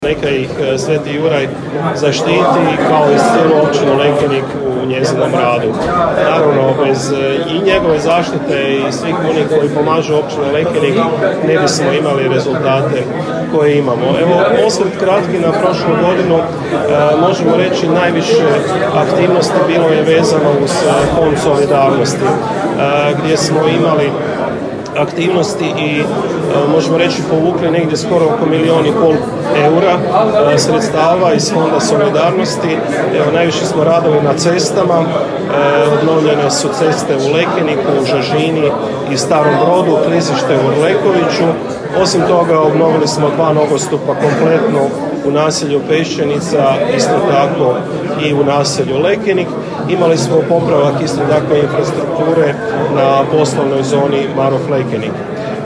Tim povodom održana je svečana sjednica Općinskog vijeća, a načelnik Ivica Perović prije svega čestitao je Dan općine svim mještanima i mještankama te onima koji slave imendan 23. travnja, na Jurjevo